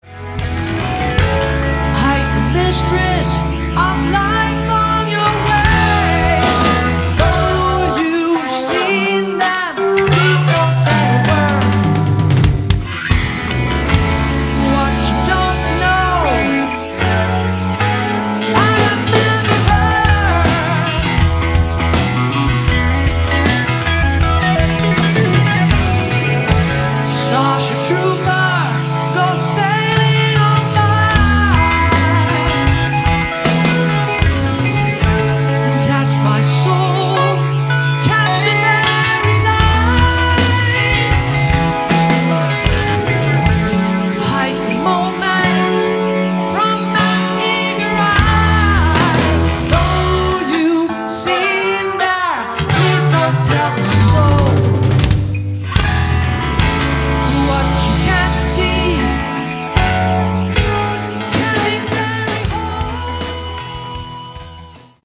Category: Prog Rock